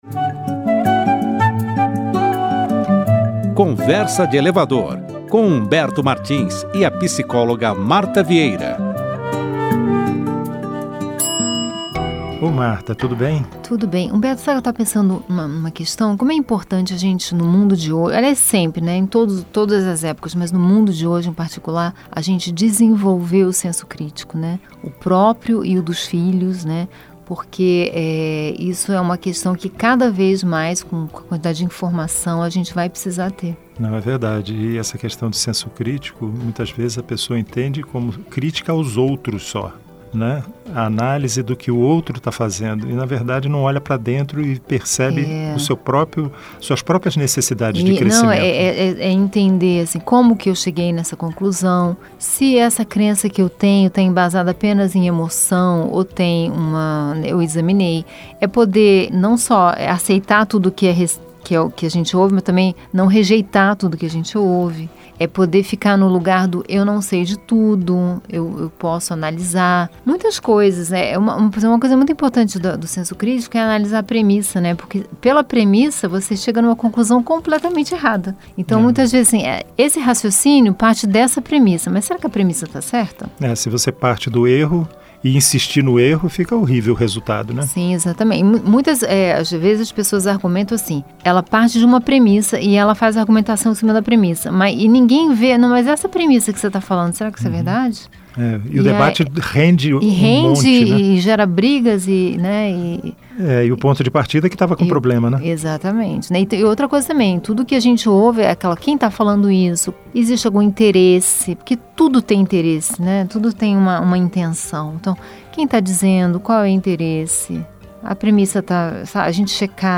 O Conversa de Elevador é um programa de bate-papo sobre temas variados, e que pretende ser ao mesmo tempo leve, breve e divertido, sem deixar de provocar uma reflexão no ouvinte.
Enfim, é uma conversa solta e sem compromisso, marcada pelas experiências do dia-a-dia e pela convivência em um mundo que às vezes parece tão rápido e tão cheio de subidas e descidas quanto um elevador.